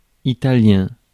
Ääntäminen
France: IPA: [i.ta.ljɛ̃]